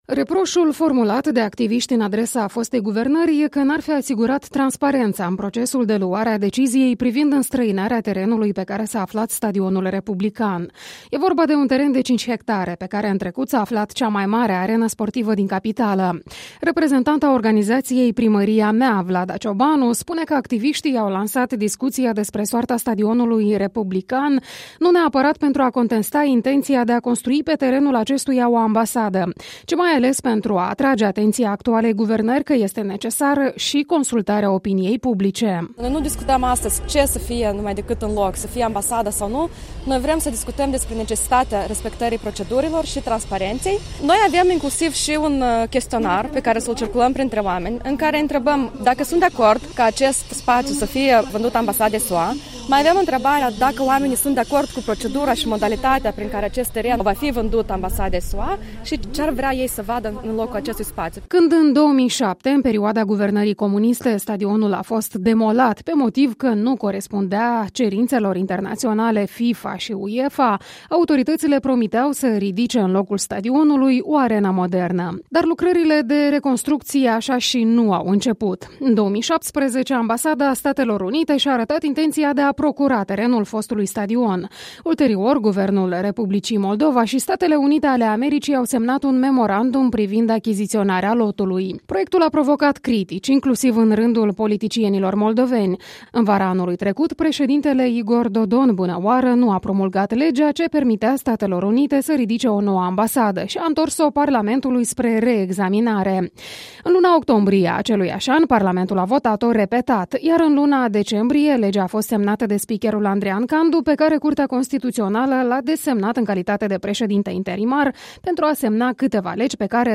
Dezbatere publică privind soarta Stadionului Republican
La poarta defunctului Stadion Republican din capitală s-au adunat astăzi după-masă reprezentanții mai multor asociații de cetățeni care țin să aibă un cuvânt de spus în felul cum se dezvoltă orașul: Centrul de Urbanism, Primăria Mea, Platzforma și Occupy Guguță. La eveniment s-au făcut auzite, din nou, critici la adresa deciziei fostului guvern de a permite construirea pe locul fostei arene sportive a noii ambasade a Statelor Unite ale Americii.